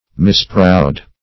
Misproud \Mis*proud"\